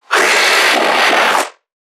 NPC_Creatures_Vocalisations_Infected [8].wav